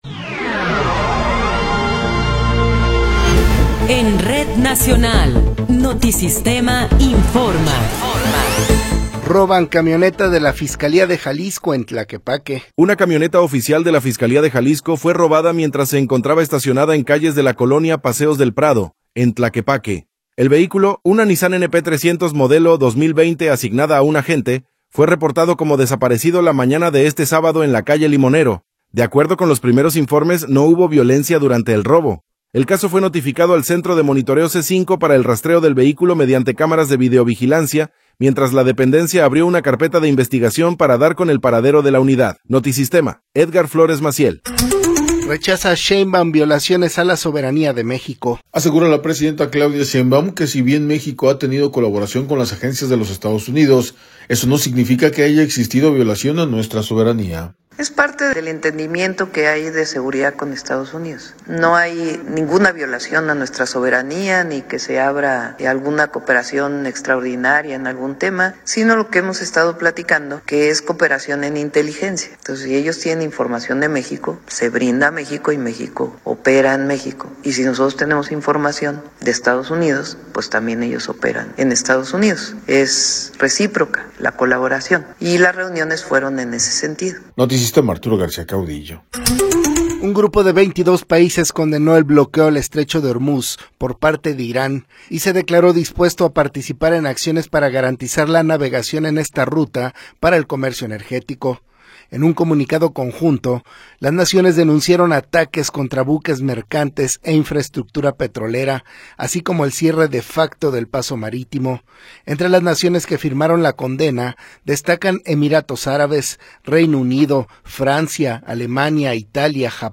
Noticiero 12 hrs. – 21 de Marzo de 2026